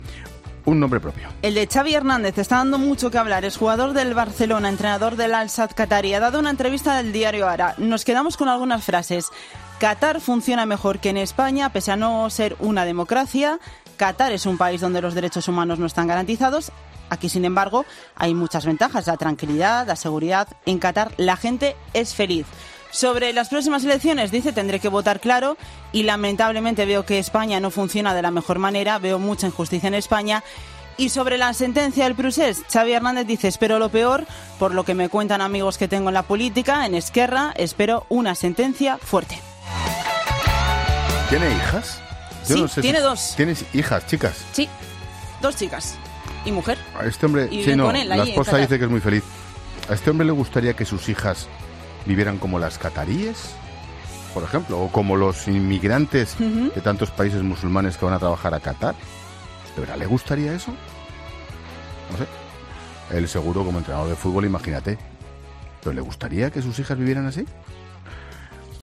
El comunicador y presentador de 'La Linterna' ha cargado contra las palabras del ex jugador del F.C. Barcelona defendiendo a Catar